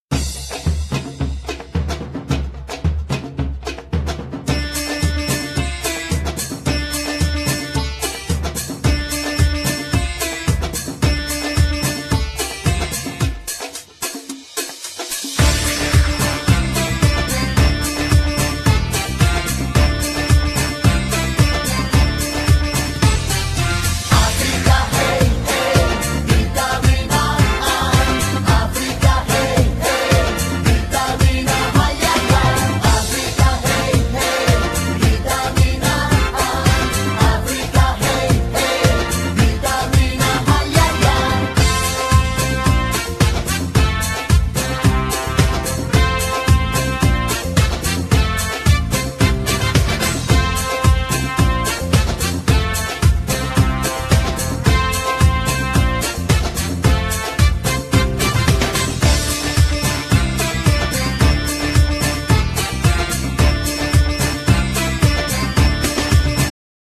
Genere : Folk - Jue